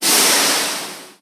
Sonido de los hidráulicos del Metro
Sonidos: Transportes
Sonidos: Ciudad